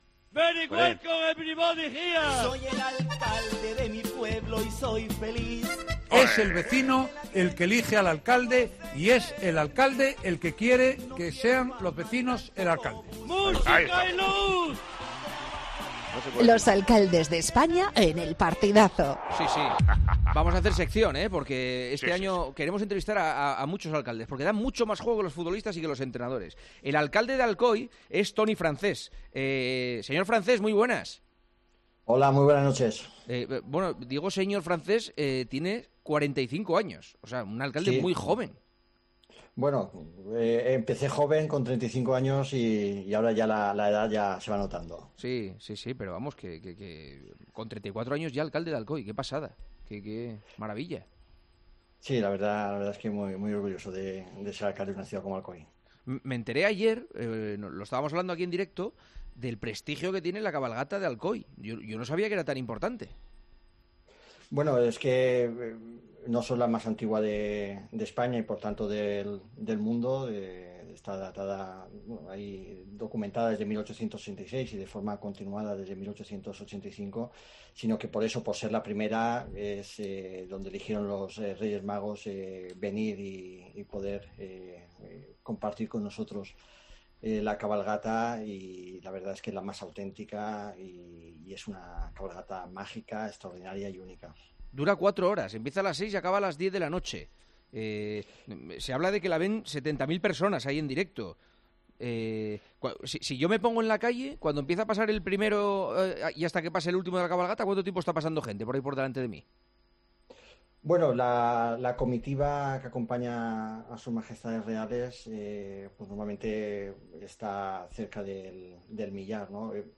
AUDIO: Hablamos con el alcalde de Alcoy del partido de Copa del Rey que enfrentará al Alcoyano contra el Real Madrid.